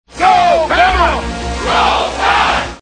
The first is the officers saying "Go Bama" and the seamen following with "Roll Tide".